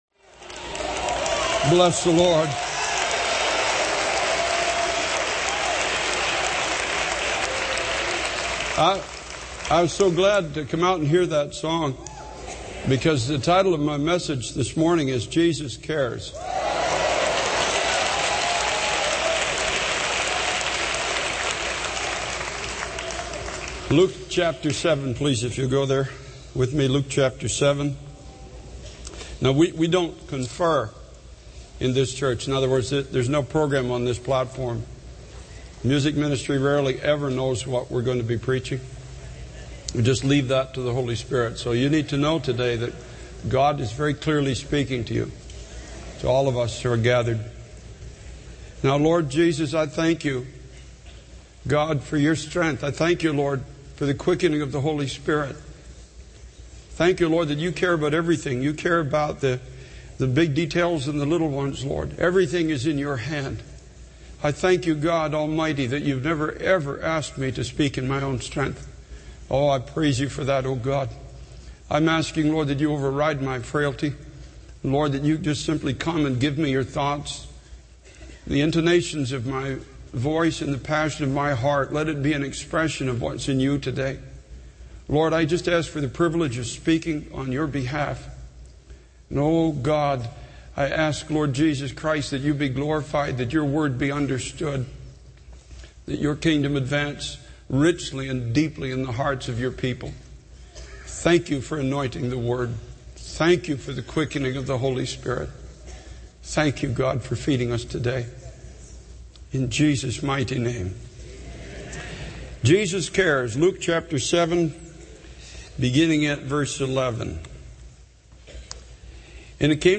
This sermon emphasizes the theme 'Jesus Cares' based on Luke chapter 7, highlighting God's compassion and care for every aspect of our lives, big or small. It encourages the audience to trust in God's strength and to bring their weaknesses and little resources to Him, believing that He can do the impossible. The message underscores the importance of understanding that Jesus cares deeply for each individual, even in the midst of struggles and accusations of indifference.